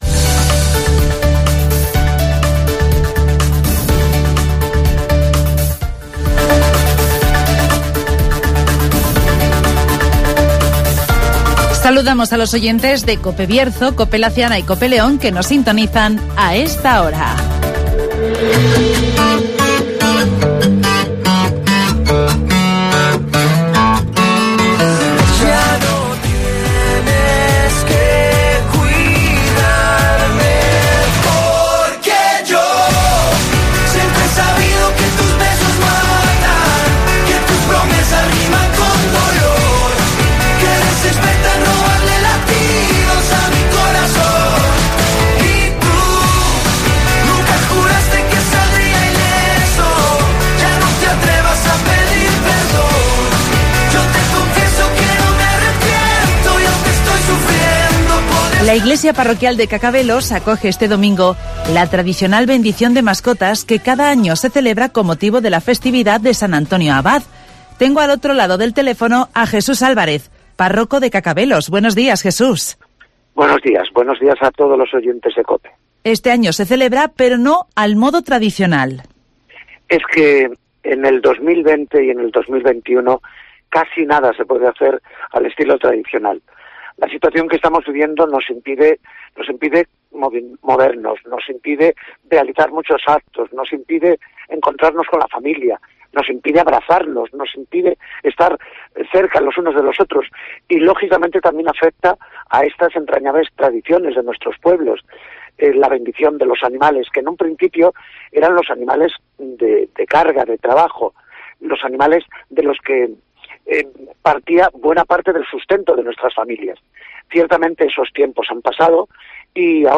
La iglesia parroquial de Cacabelos acoge este domingo la tradicional bendición de mascotas (Entrevista